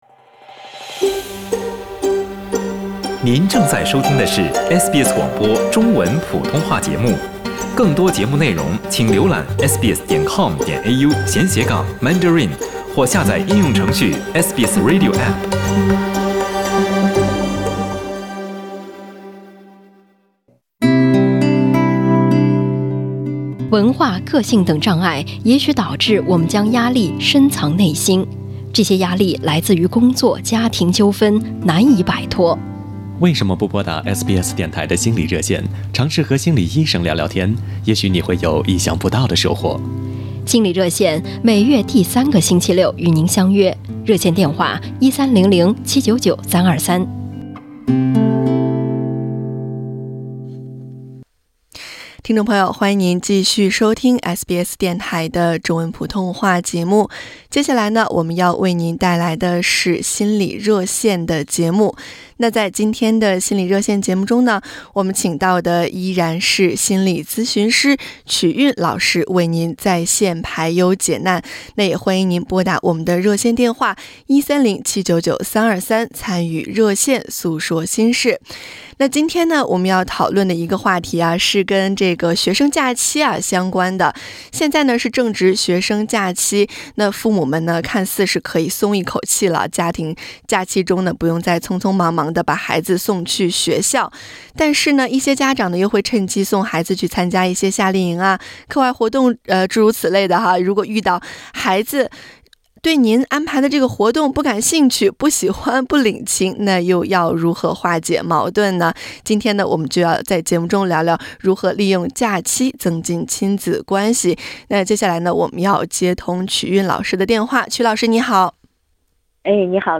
欢迎点击封面音频，收听完整采访。